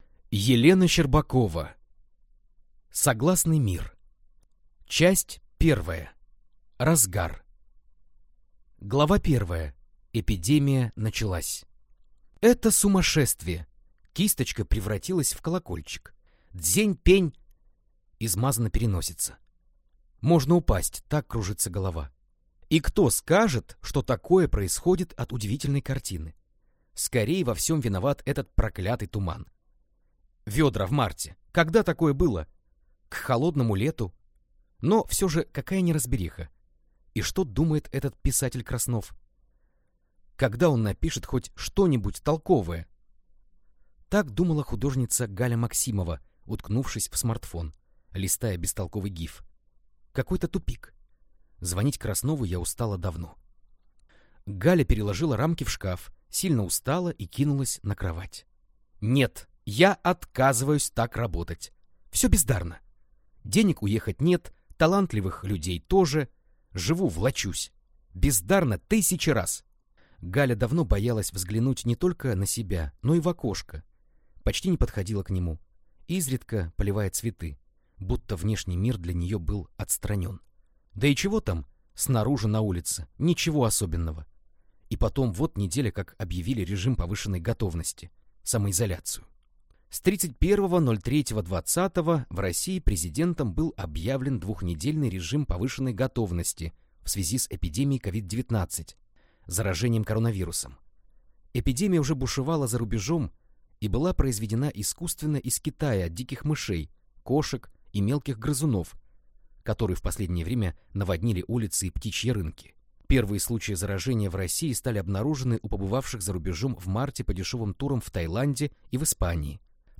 Аудиокнига Согласный мир | Библиотека аудиокниг